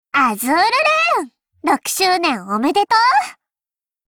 碧蓝航线:英雄 碧蓝航线/六周年登录语音